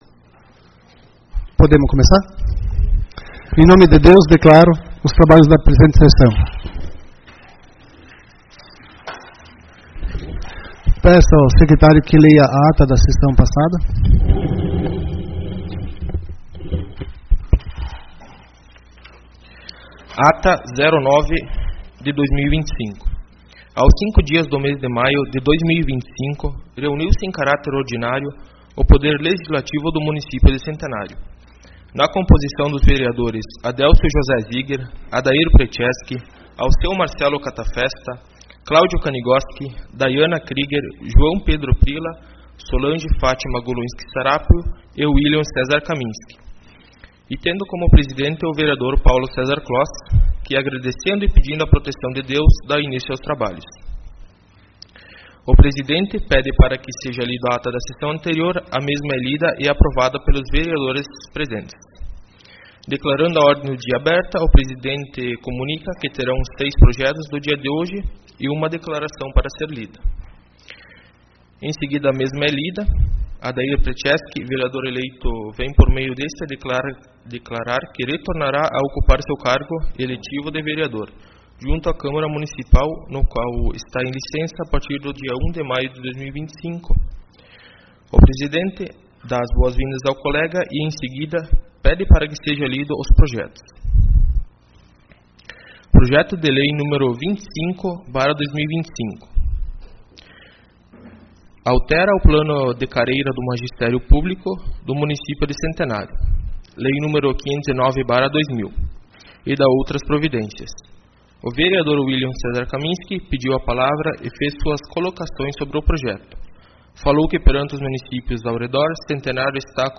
Sessão Ordinária 16/05/2025